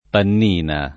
[ pann & na ]